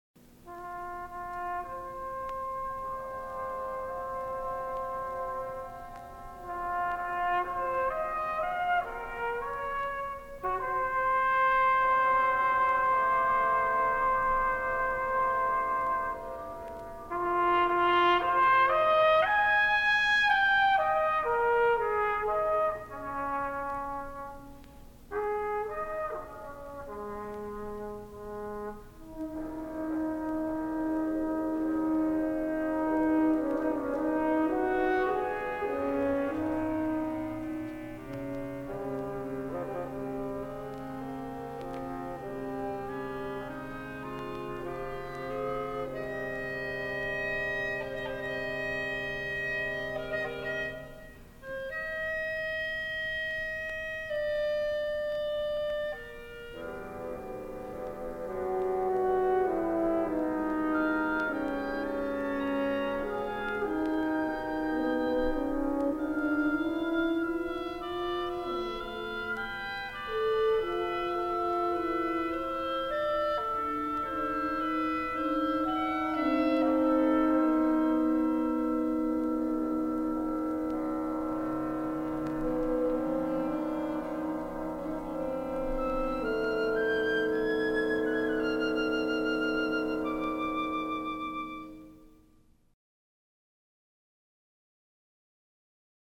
Symphonic Band